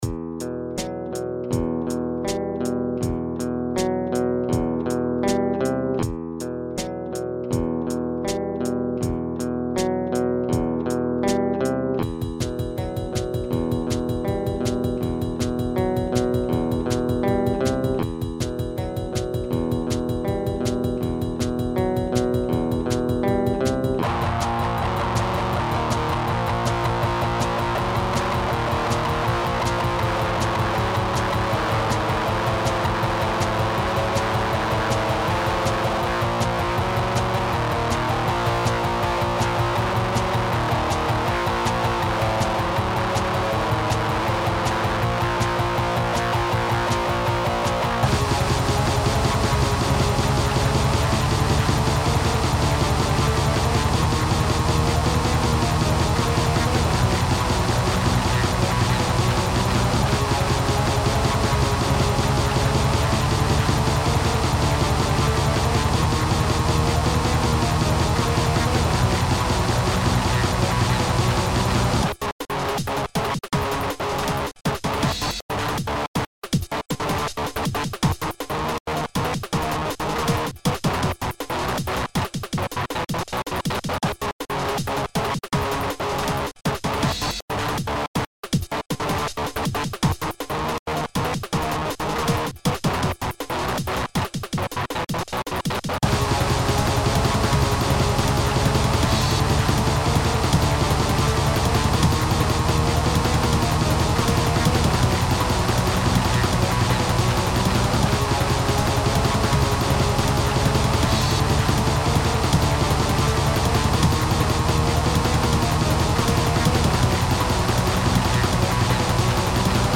Metal was creeping back into my musicality.
Shoegaze/black metal/breakcore song.